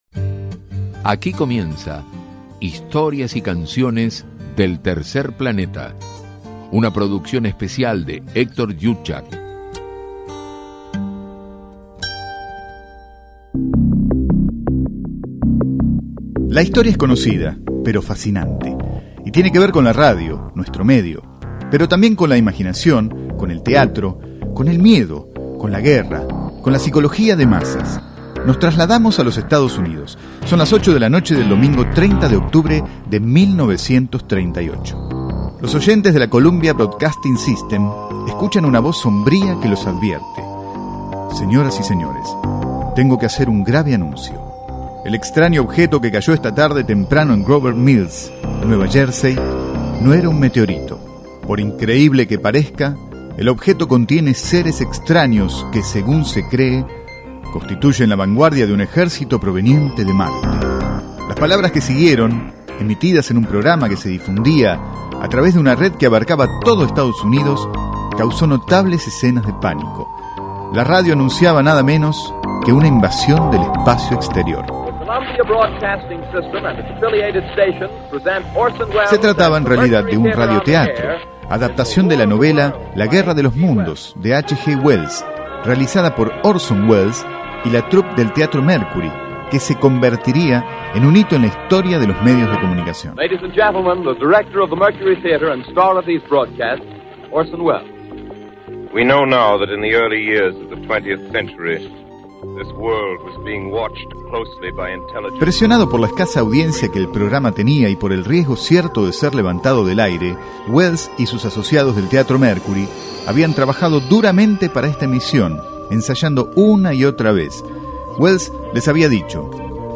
El prestigioso actor, guionista y director estadounidense en su recordado radiotetro de la Columbia Broadcasting System (CBS) basado en el libro la "Guerra de los Mundos".
orson_welles_cbs.mp3